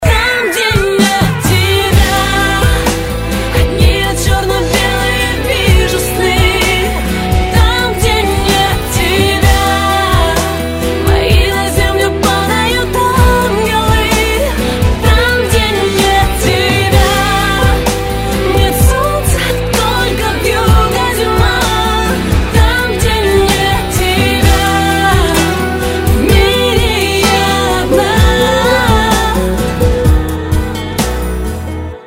Категория: Спокойные рингтоны